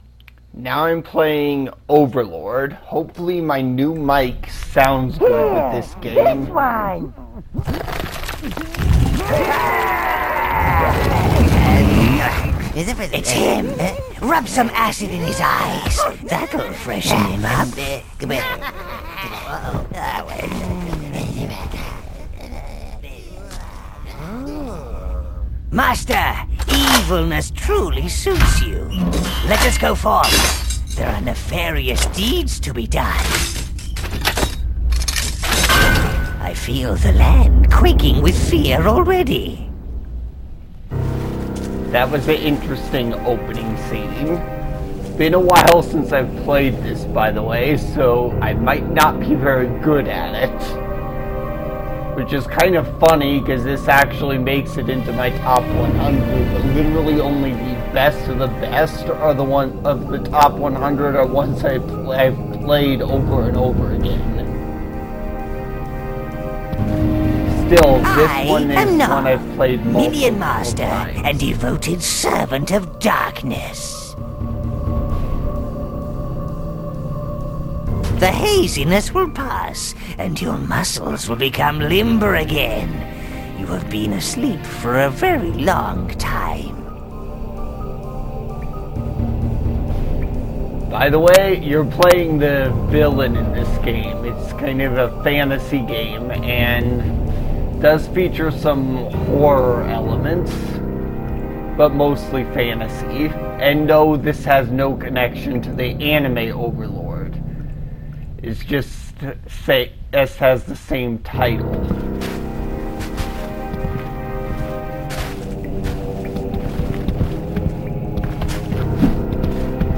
I play Overlord with commentary